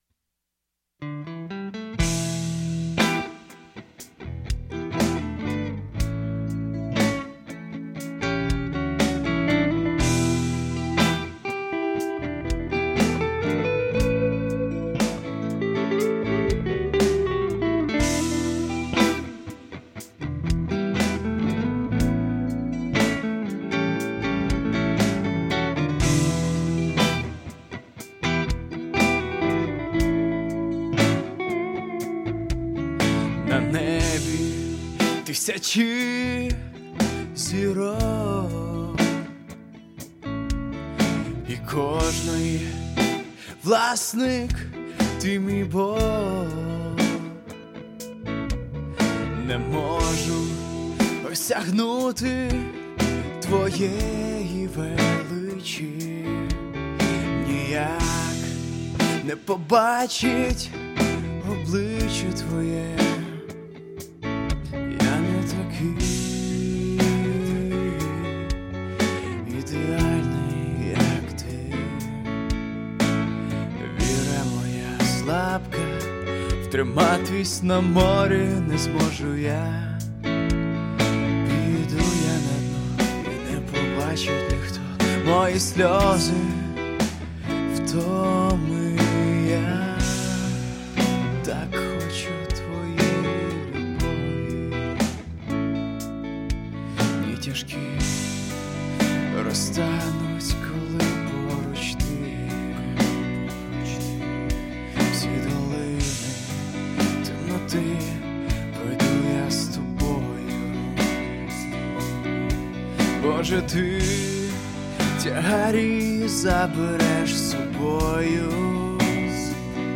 356 просмотров 123 прослушивания 4 скачивания BPM: 60